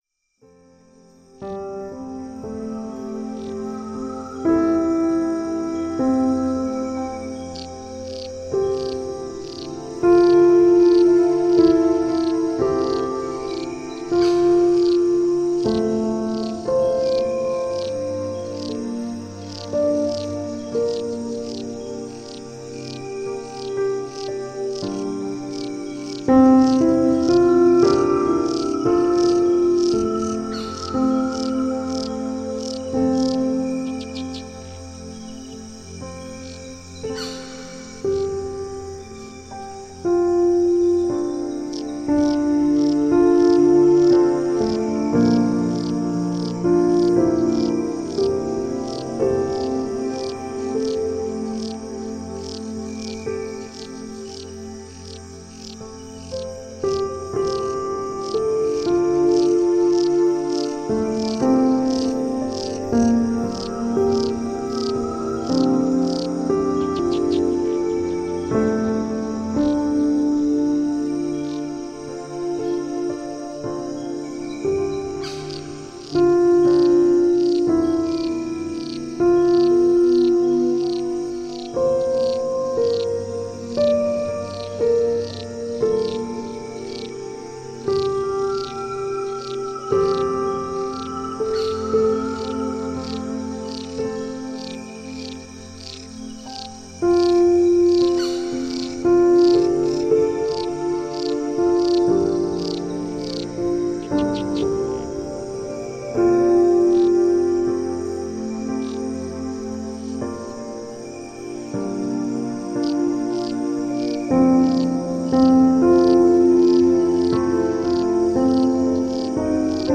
Track1_Soothing_Instrumental.mp3